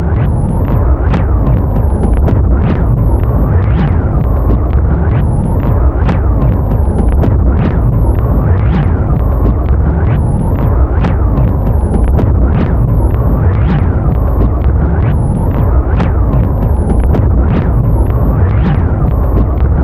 卷到卷的磁带操纵/噪音循环" 尖叫声惠勒
描述：从磁带操作上看，用1/4"磁带录制的鸣叫声和物理循环
Tag: 啁啾 磁带操纵 带环